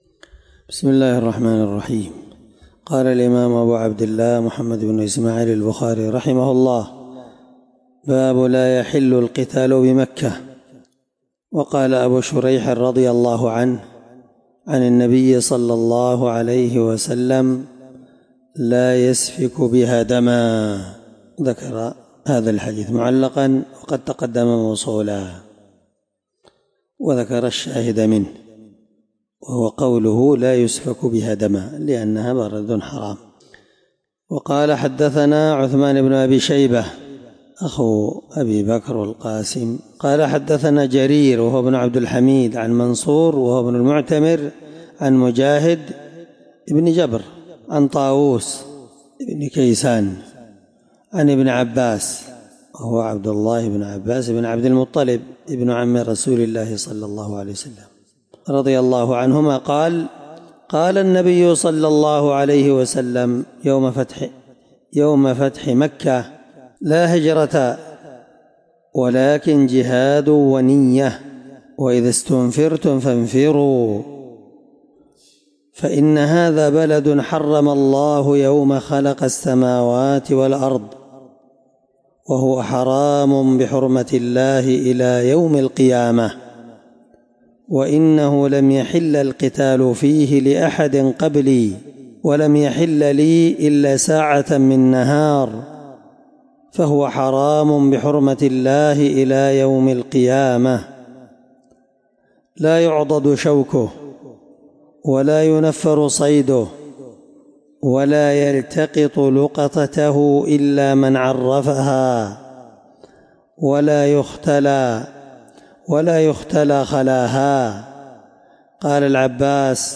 الدرس 10من شرح كتاب جزاء الصيد حديث رقم(1834 )من صحيح البخاري